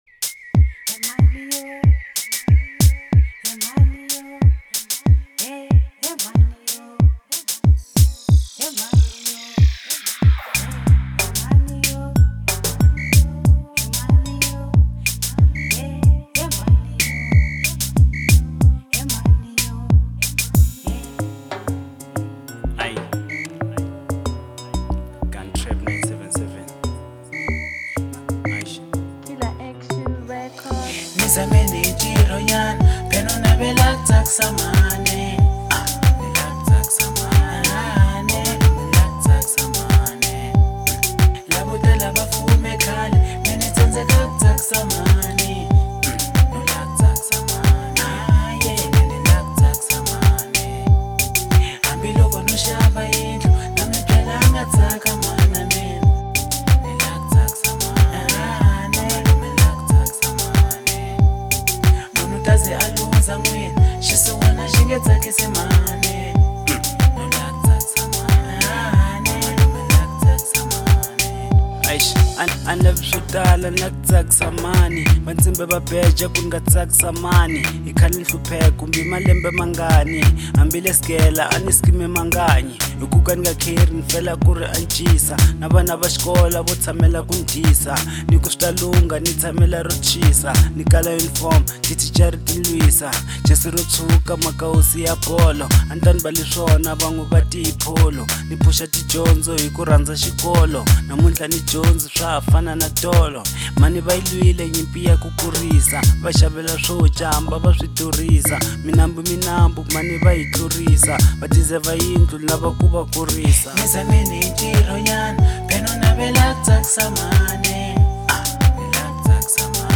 04:30 Genre : African Disco Size